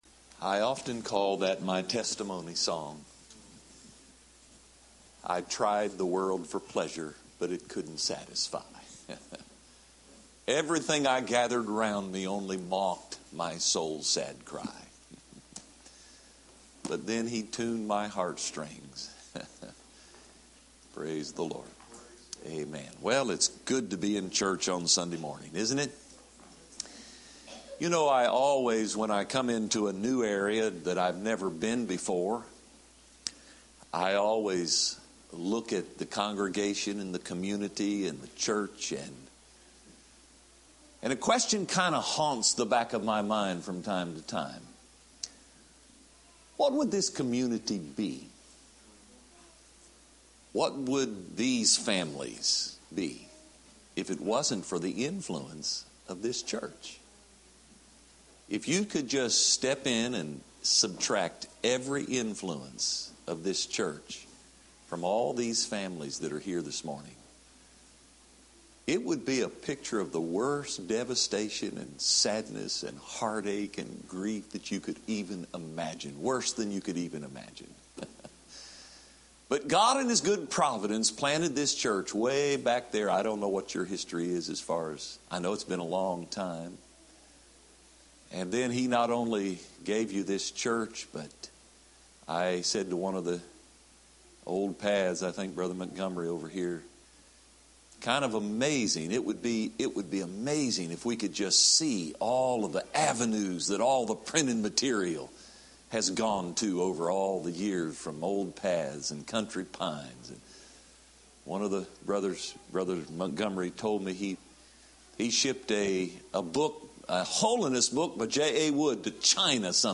Series: Spring Revival 2016 Tagged with calvary , jesus , salvation , sin , the cross